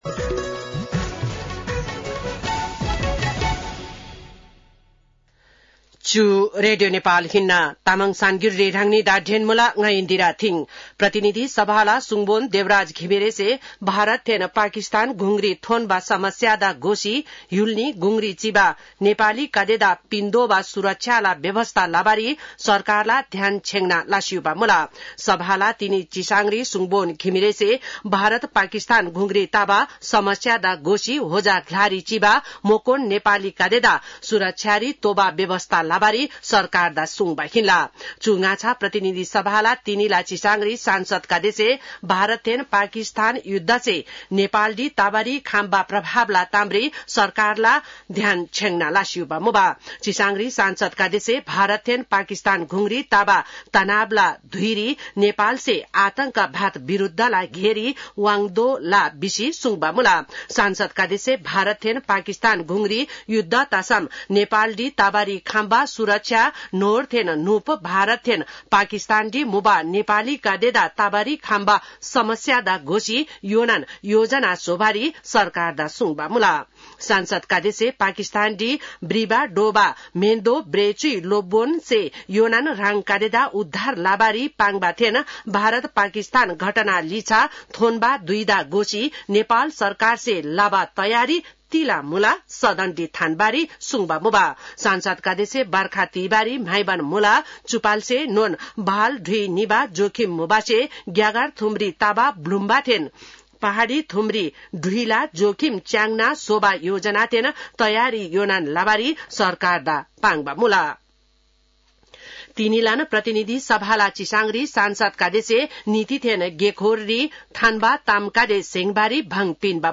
तामाङ भाषाको समाचार : २५ वैशाख , २०८२
Tamang-news-1-27.mp3